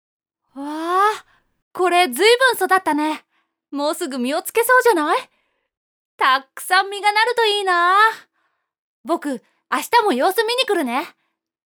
【サンプルセリフ】
（柏木がうまく実がなるようにお世話している木に向かって嬉しそうに）